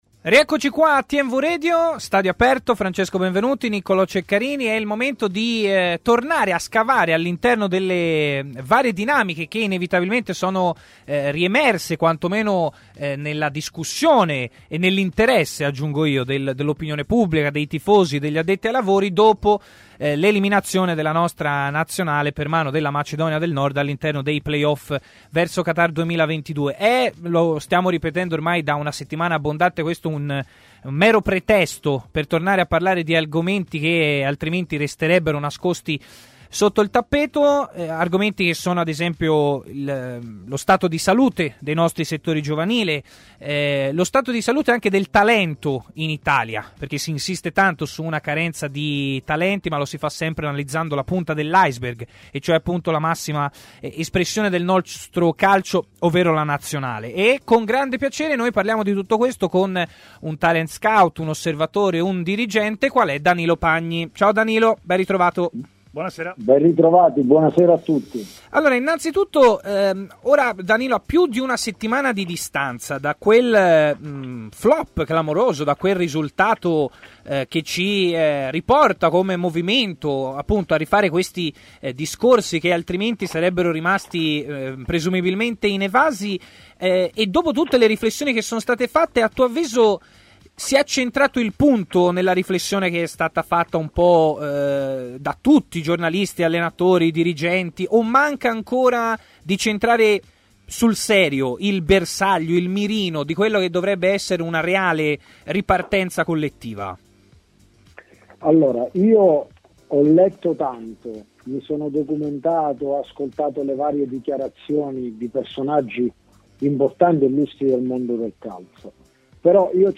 è intervenuto a TMW Radio, durante Stadio Aperto, per parlare dei problemi del calcio italiano.